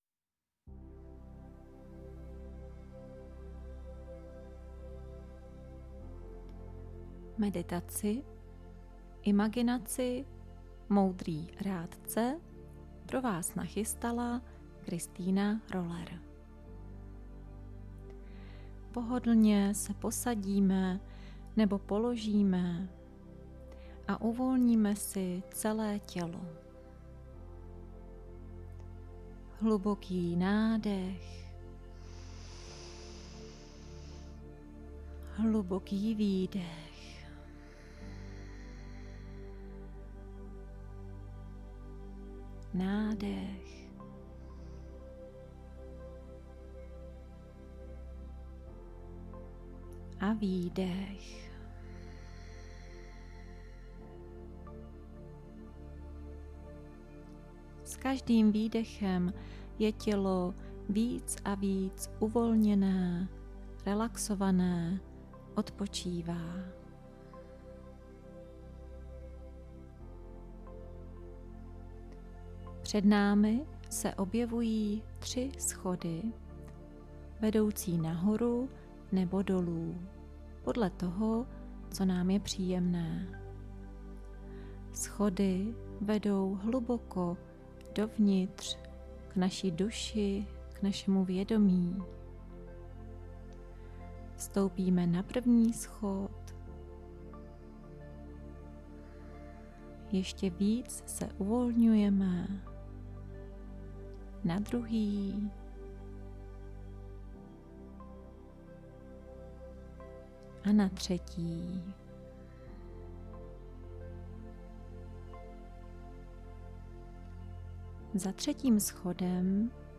meditace Moudrý rádce